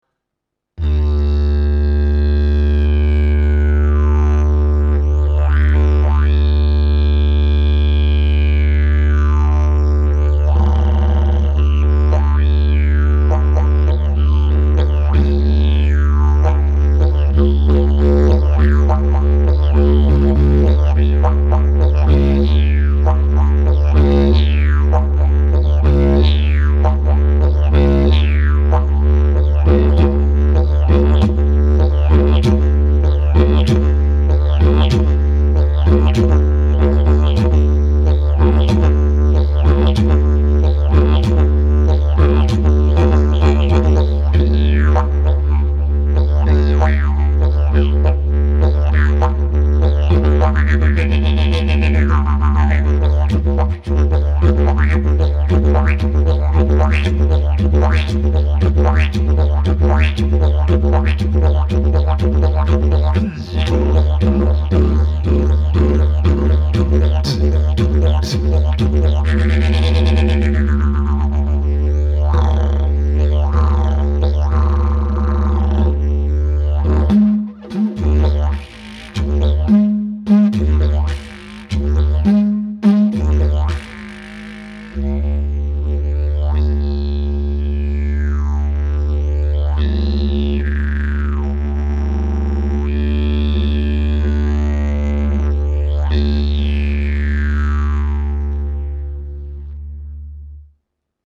Le Didgeridoo en Bambou
Un son rond et riche en harmoniques (les sons aigües) résonnera rapidement.
Ils sont faciles à jouer, leur son est riche en harmoniques et bien équilibré.
bambou-standard.mp3